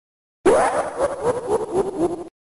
Download Sonic Exe Laugh sound effect for free.
Sonic Exe Laugh